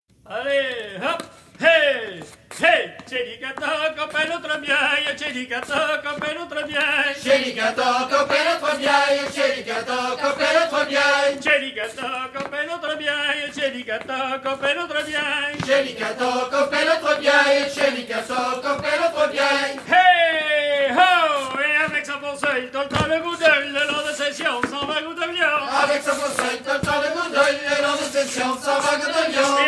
danse : ronde : grand'danse
Pièce musicale éditée